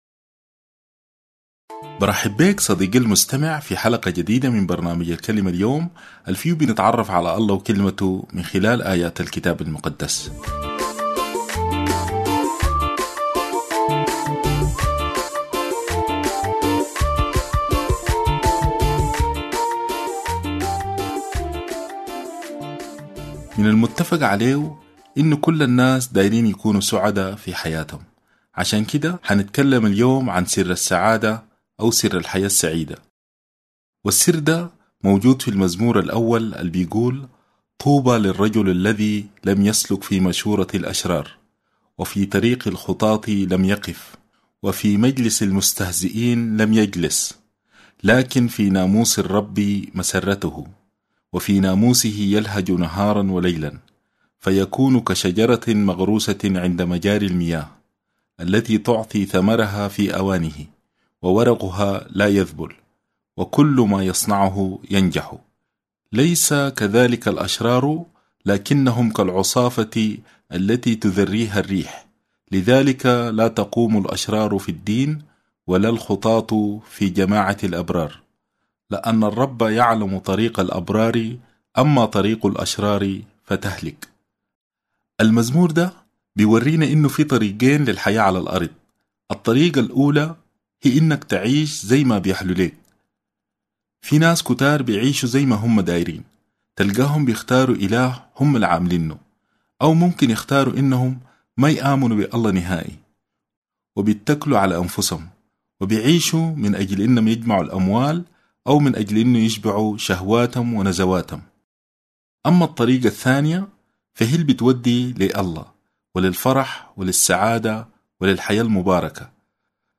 الكلمة اليوم باللهجة السودانية